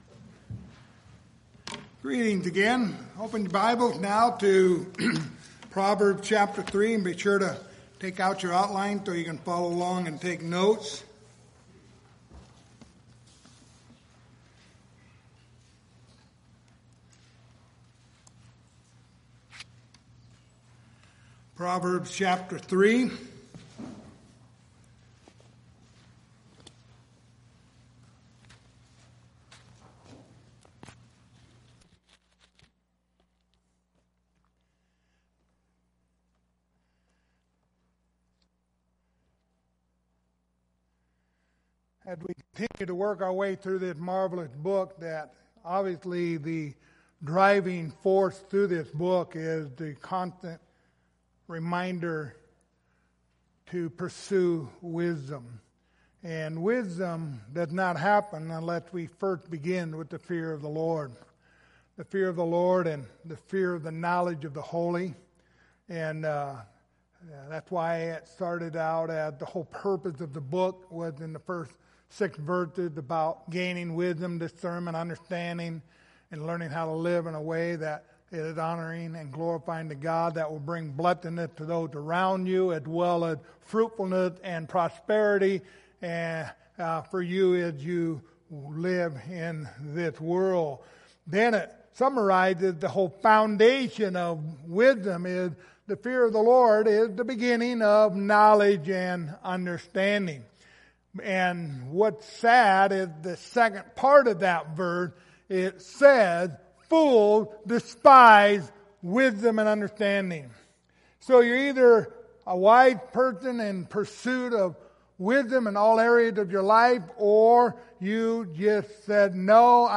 The Book of Proverbs Passage: Proverbs 3:19-26 Service Type: Sunday Morning Topics